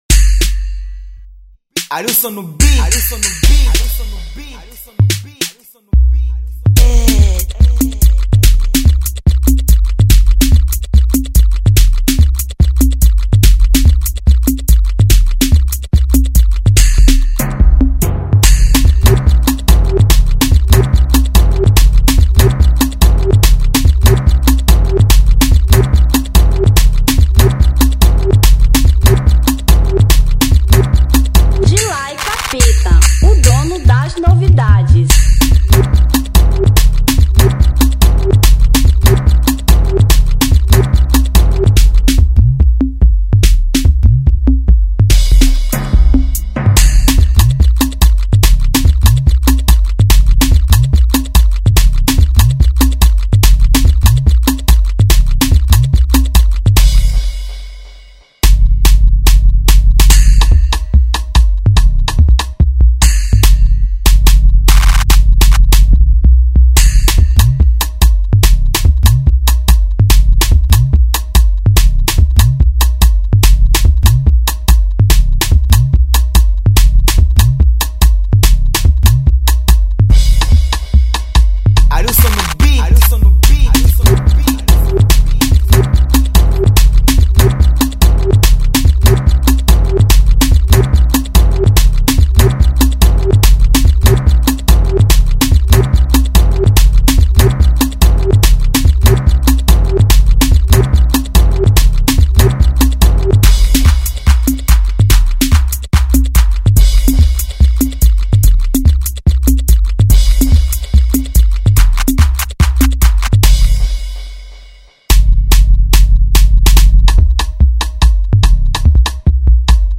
Instrumental 2023